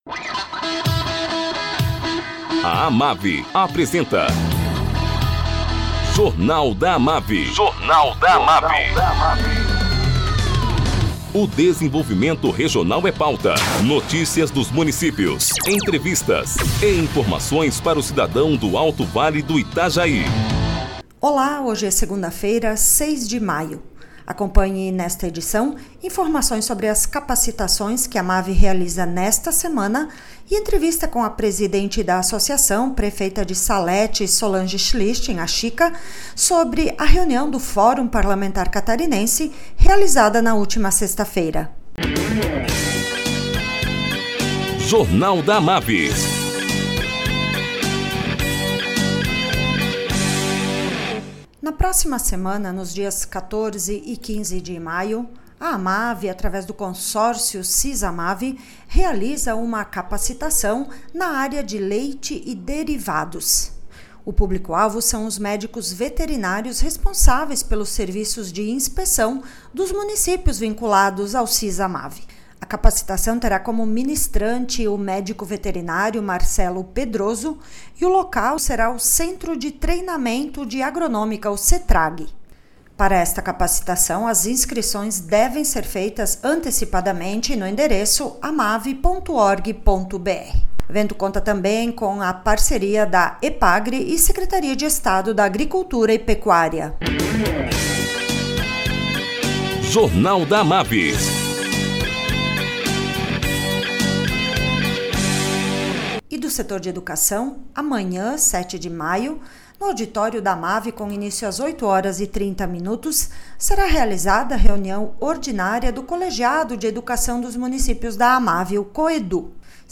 Presidente da AMAVI, prefeita Chica, fala sobre as prioridades regionais defendidas durante o Encontro do Fórum Parlamentar Catarinense, na última sexta-feira em Blumenau.